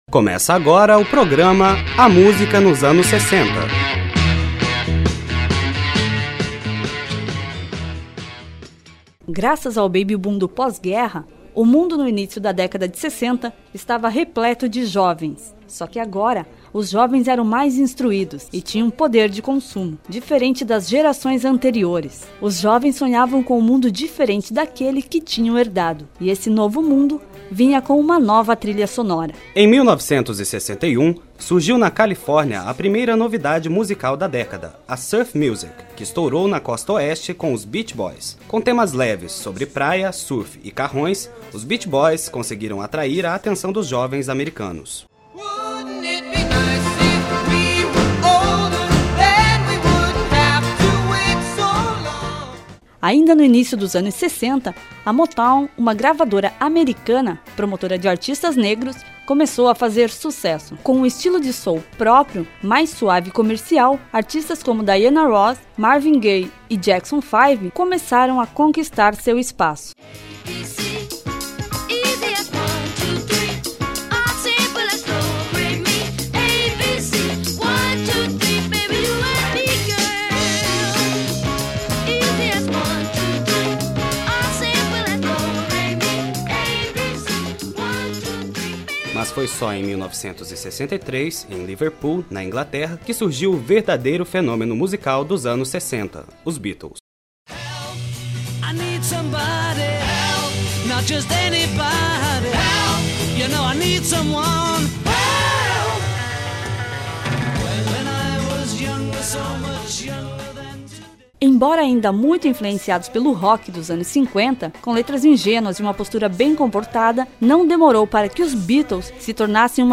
Abstract: Um relato que una a música ao contexto histórico dos anos de 1960. A história da época é narrada de forma cronológica e a trilha sonora fica por conta dos maiores nomes da década como The Beach Boys, The Beatles, The Who, Bob Dylan, entre outros.